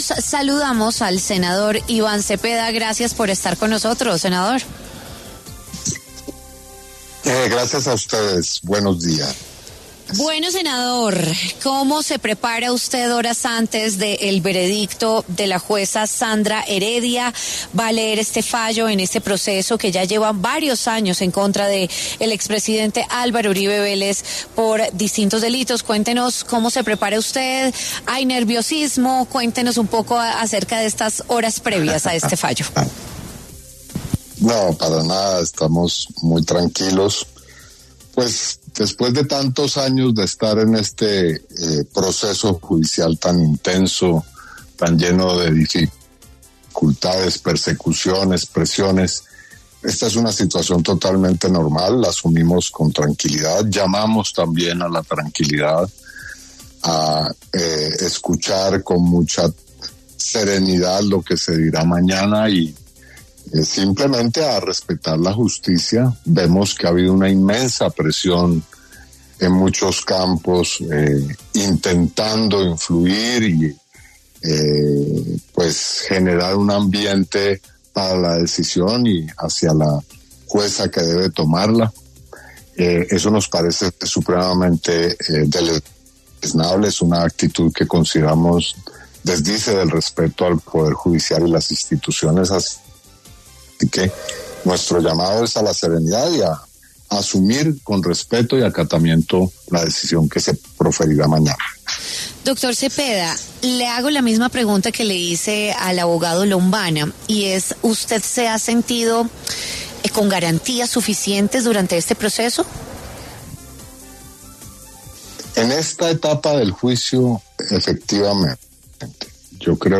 El senador Iván Cepeda habló en W Fin de Semana sobre el sentido de fallo en el caso contra Álvaro Uribe que se conocerá mañana, 28 de julio.